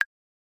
tink.mp3